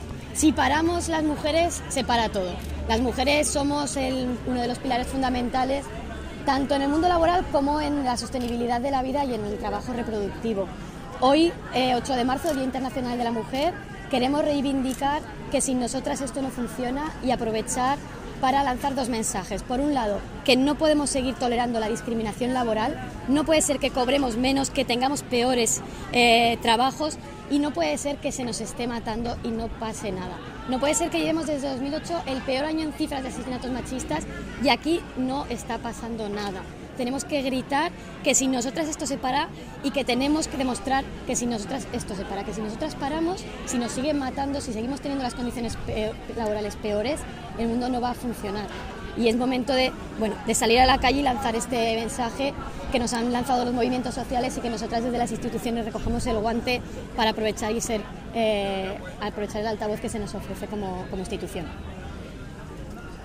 DECLARACIONES DE LA CONCEJALA ARANTZA GRACIA